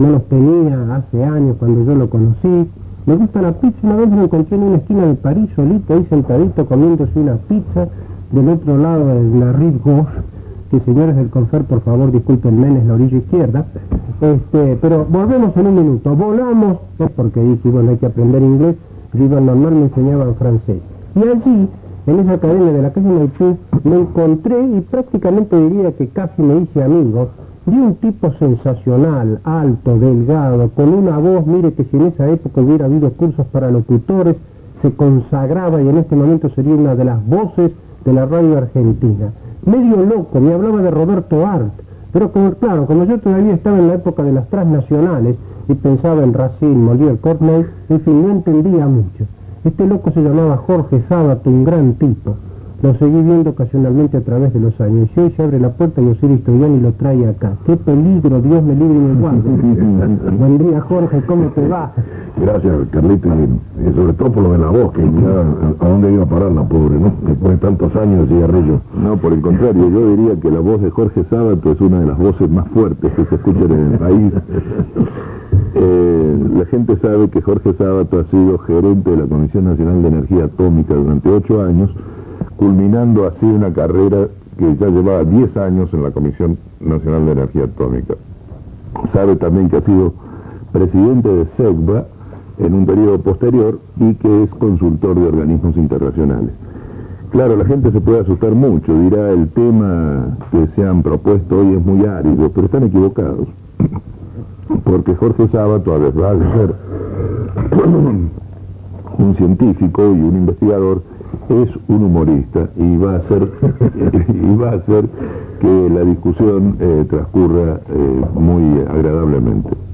Entrevista en los ciclos "Ciudad abierta"